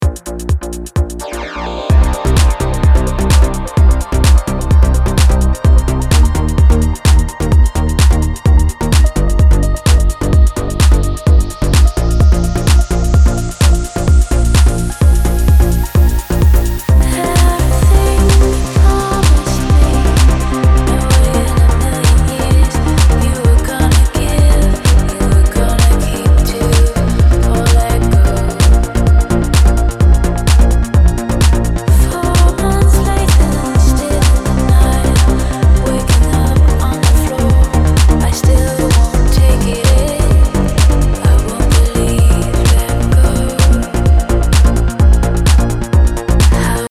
Оригинальный у вас темп проекта ) 128.08 Ради интереса по-быстрому подложил под ваш рисунок баса другой бас, с мяском. Плюс пара манипуляций с эквалайзером.